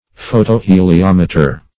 Search Result for " photoheliometer" : The Collaborative International Dictionary of English v.0.48: Photoheliometer \Pho`to*he`li*om"e*ter\, n. [Photo- + heliometer.]
photoheliometer.mp3